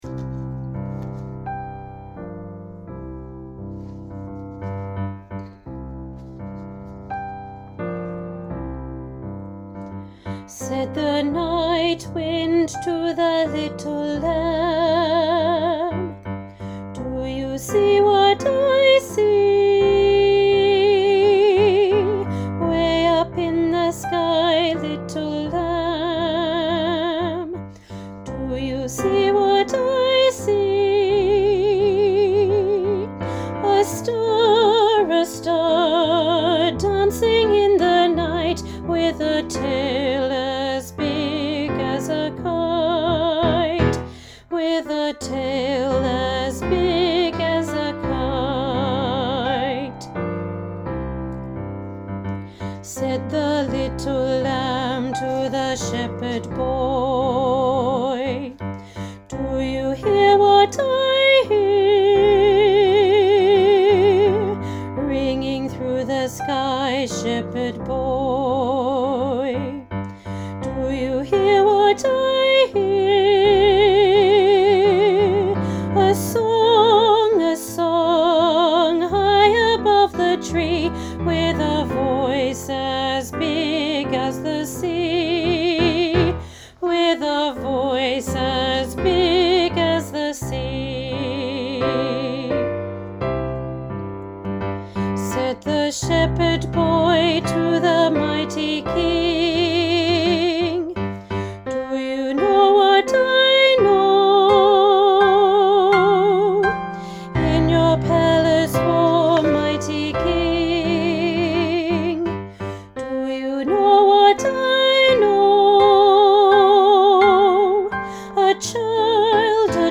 Junior Choir – Do You Hear What I Hear – Alto